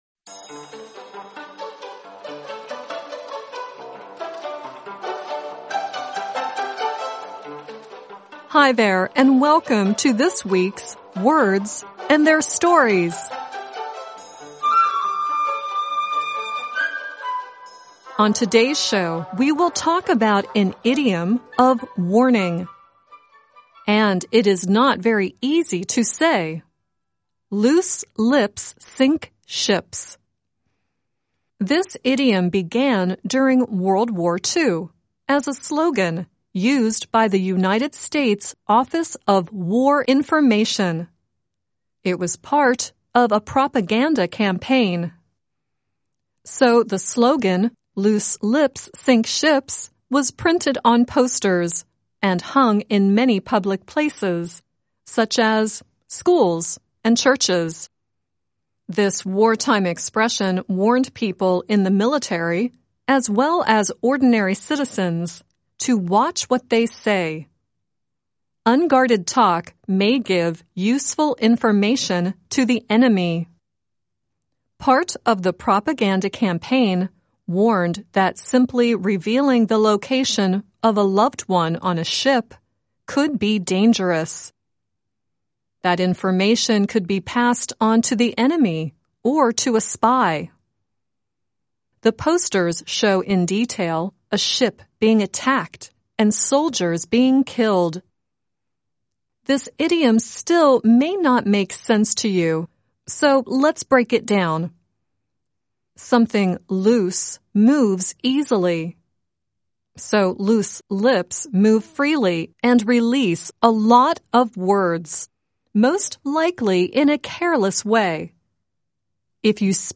The song at the end is Spandau Ballet singing "Swept."